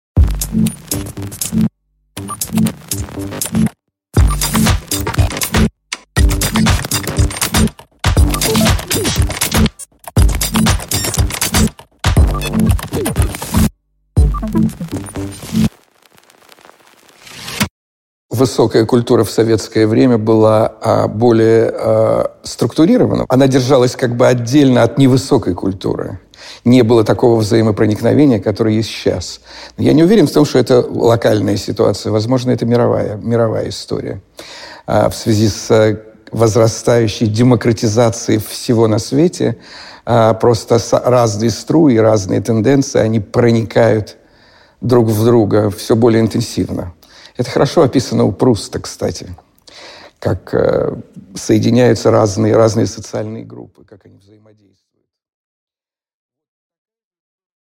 Аудиокнига Судьба высокой культуры в постсоветскую эпоху | Библиотека аудиокниг